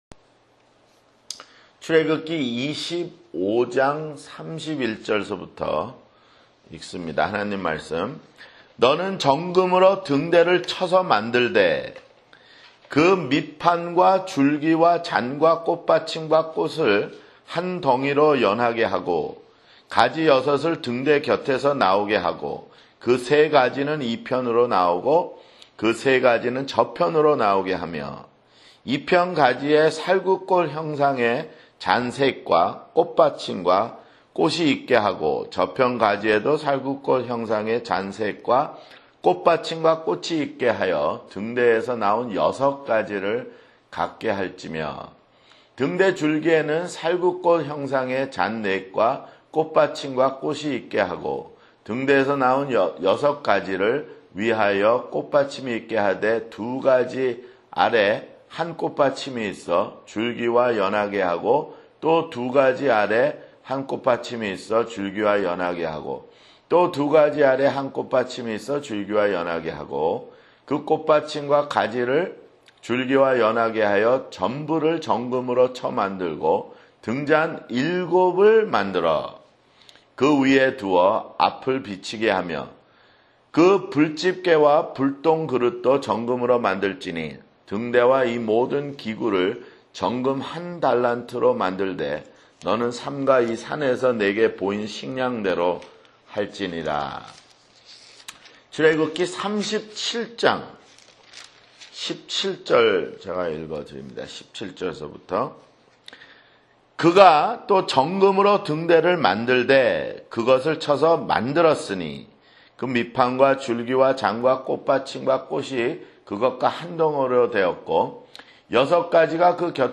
[성경공부] 출애굽기 (68)